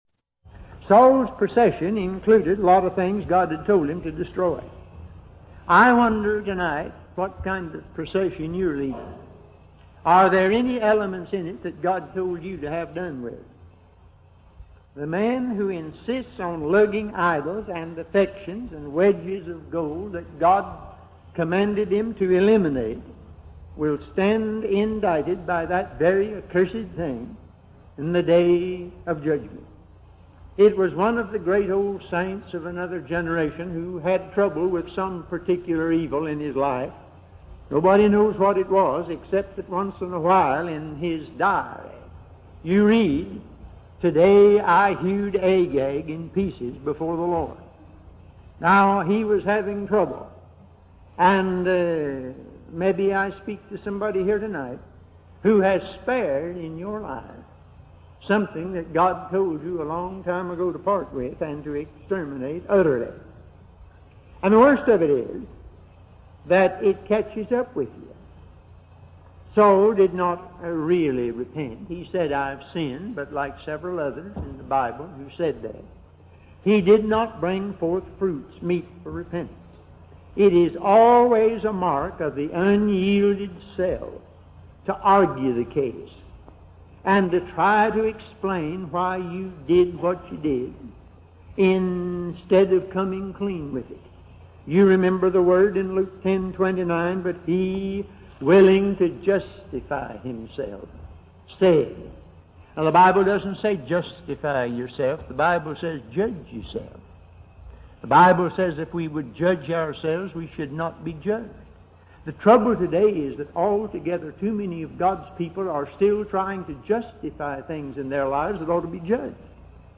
In this sermon, the preacher reflects on his own experiences and the importance of humility and submission before God. He emphasizes the need for grace and the willingness to be used by God. The preacher also discusses the consequences of stubbornness and the importance of listening to God's reproof.